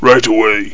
Amiga 8-bit Sampled Voice
soldier-jim-ok0.mp3